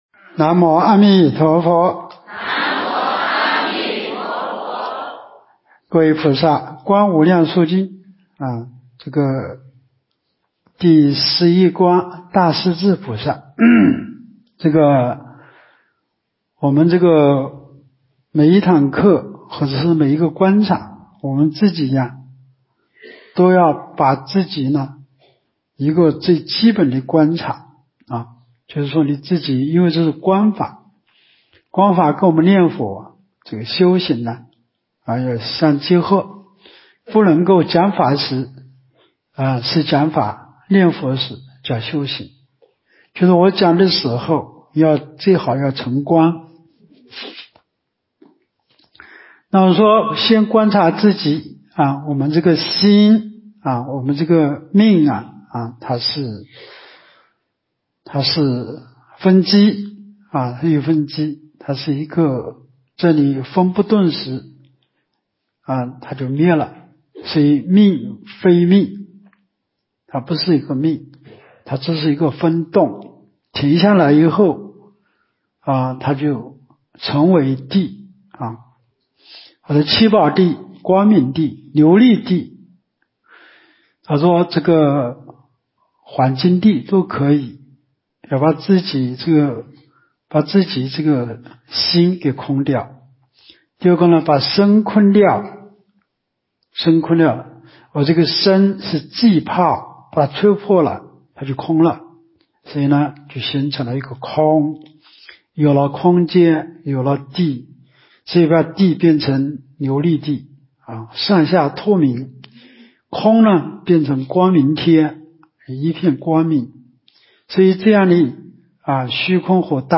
无量寿寺冬季极乐法会精进佛七开示（30）（观无量寿佛经）...